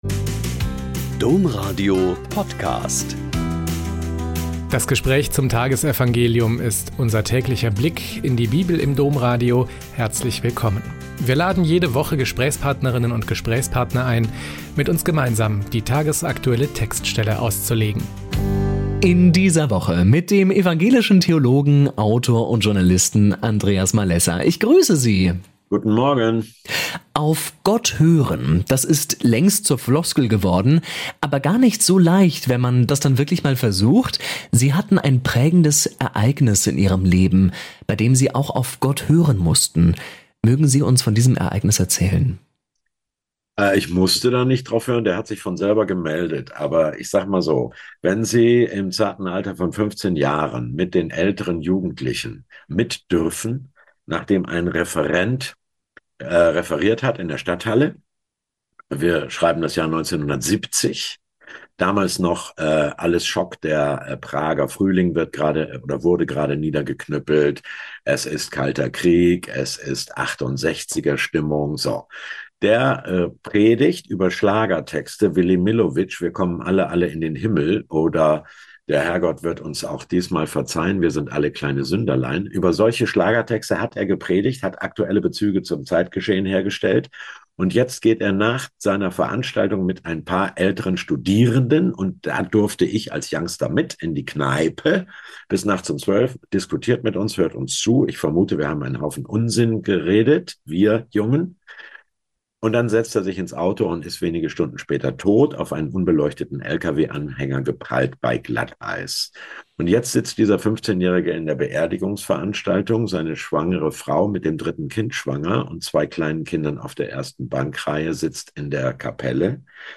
Lk 16,1-8 - Gespräch